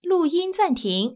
ivr-recording_paused.wav